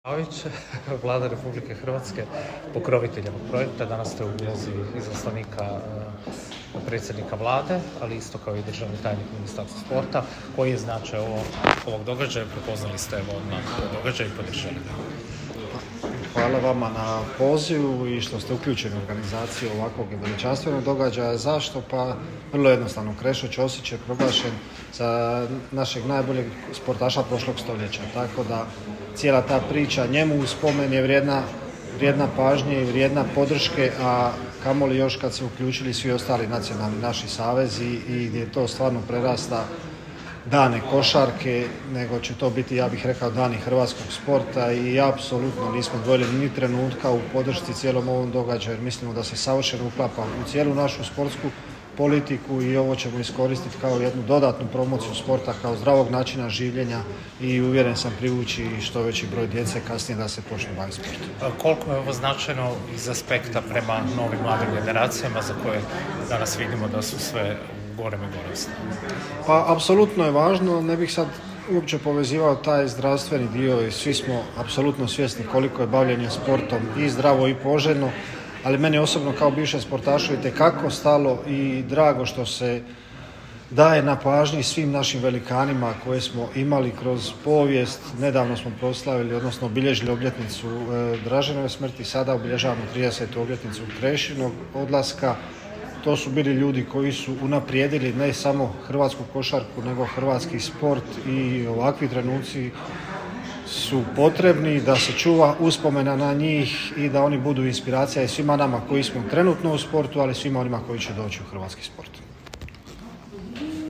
> Josip Pavić, državni tajnik u Ministarstvu turizma i sporta